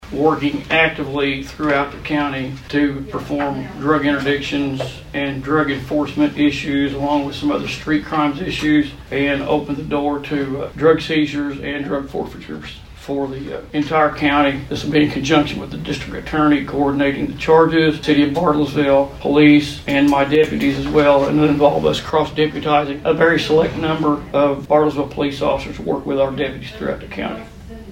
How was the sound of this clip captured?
Washington County Commissioners approve two law enforcement agreements during a Monday meeting.